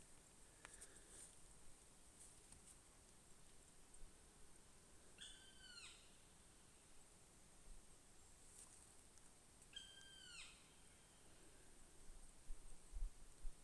Putni -> Dzeņi ->
Melnā dzilna, Dryocopus martius
StatussDzirdēta balss, saucieni